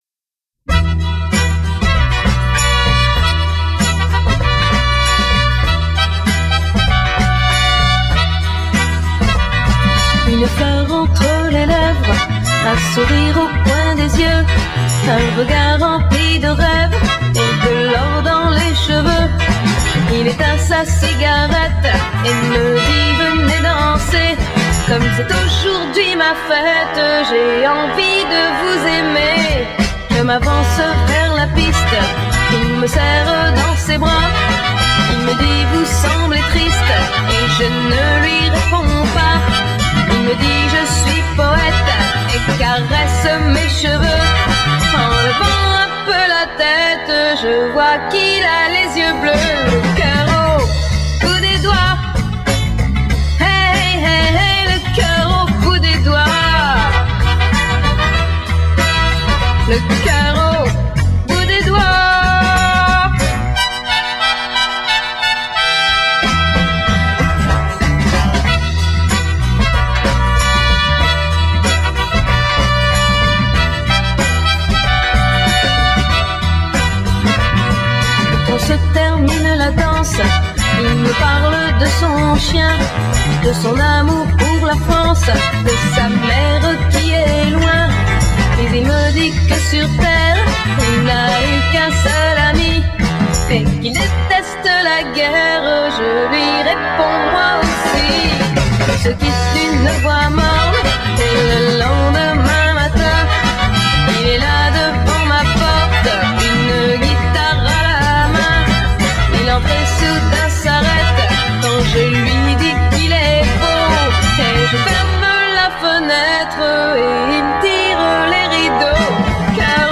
Chanson